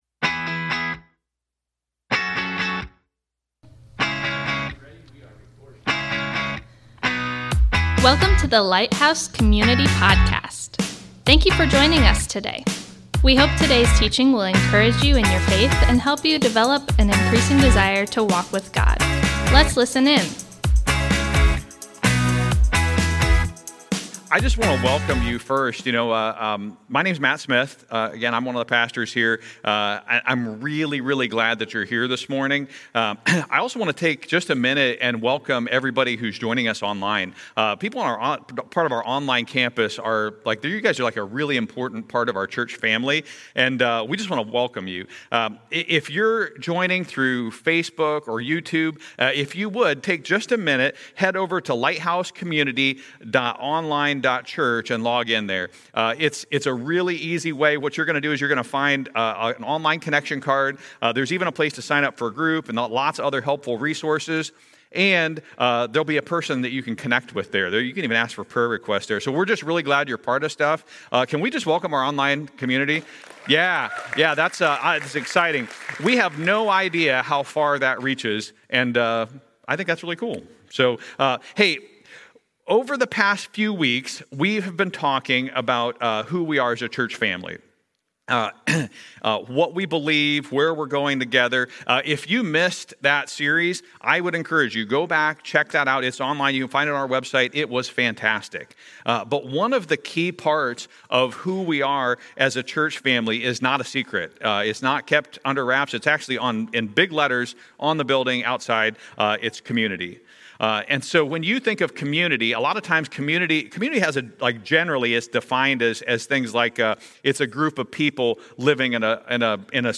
Thank you for joining us today as we gather to worship!
This morning, we’ll be walking through Acts 2:42–47 together in a small group setting giving you a real-time experience of what Lighthouse small groups are all about. As we study, you’ll see how the example of the early church highlights the importance and power of doing life together in small groups.